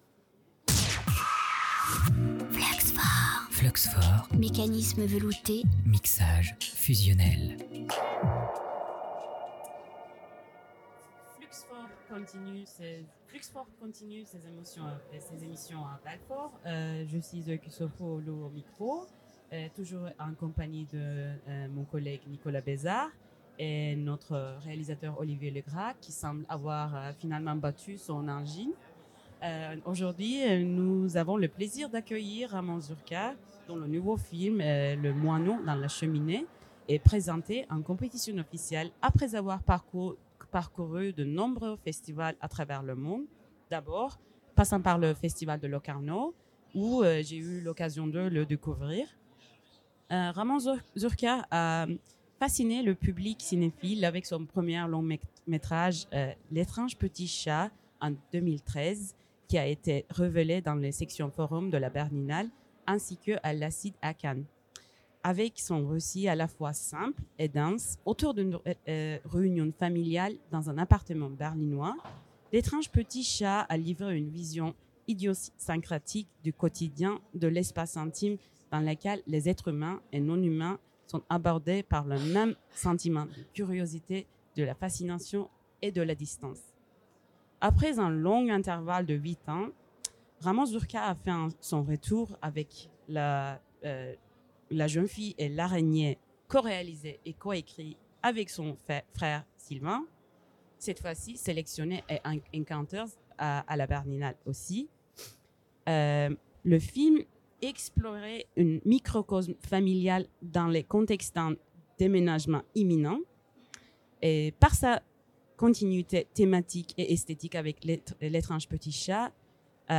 Rencontre avec Ramon Zürcher, Entrevues 2024